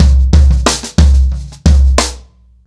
• 122 Bpm Drum Loop C Key.wav
Free drum loop sample - kick tuned to the C note. Loudest frequency: 917Hz
122-bpm-drum-loop-c-key-4uB.wav